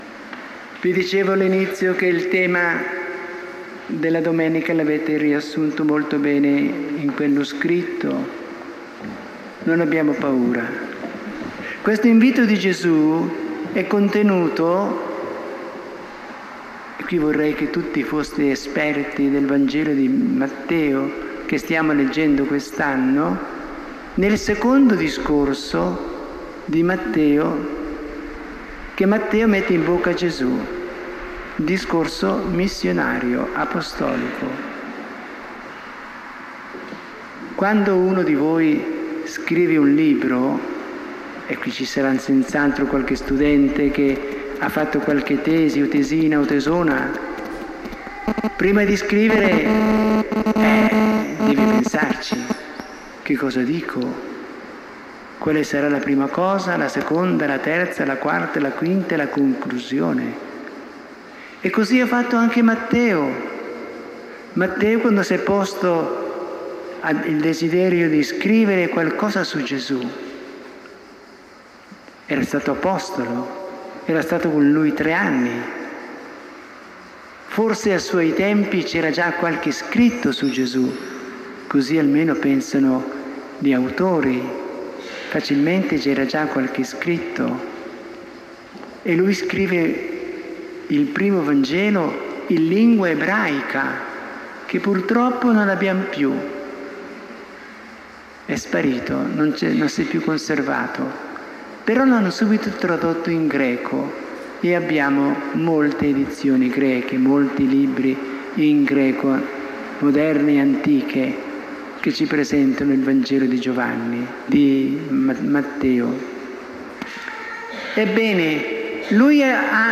Omelia domenica 25 giugno